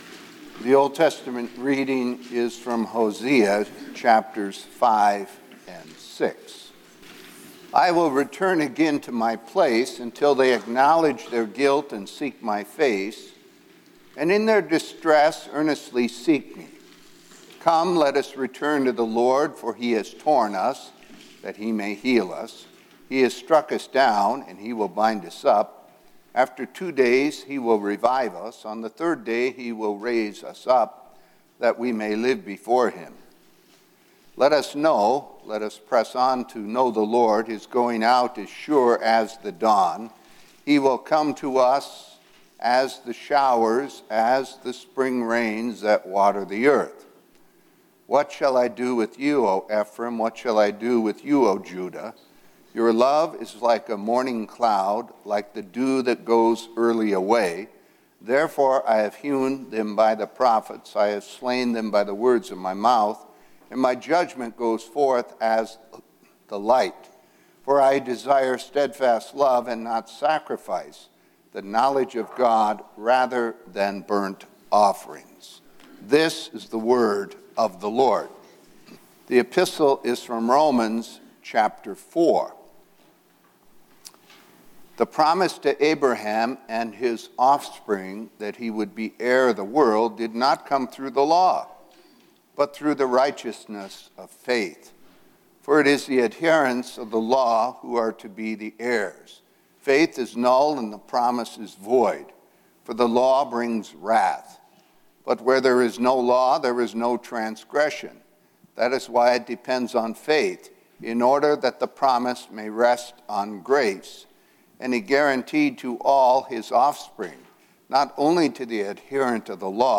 Word & Sermon Weekly – Second Sunday after Pentecost -June 11, 2023